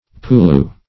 Search Result for " pulu" : The Collaborative International Dictionary of English v.0.48: Pulu \Pu"lu\, n. A vegetable substance consisting of soft, elastic, yellowish brown chaff, gathered in the Hawaiian Islands from the young fronds of free ferns of the genus Cibotium , chiefly Cibotium Menziesii ; -- used for stuffing mattresses, cushions, etc., and as an absorbent.